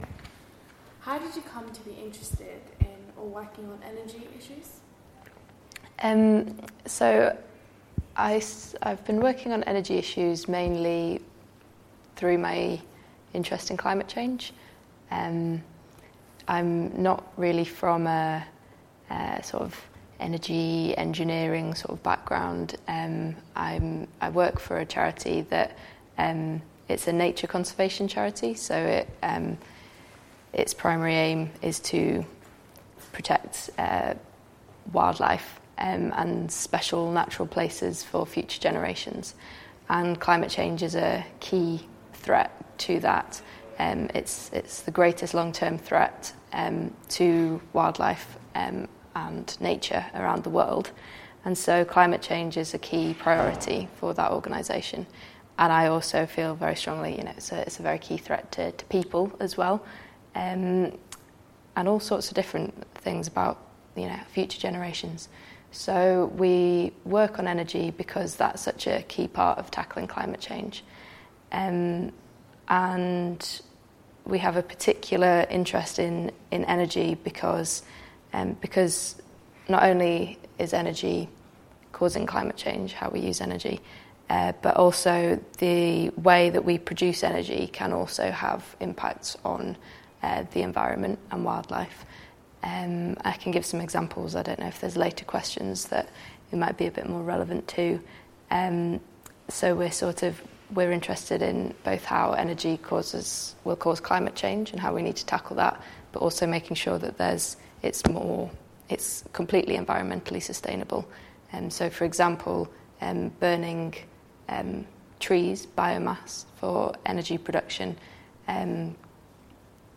An Energy Generation interview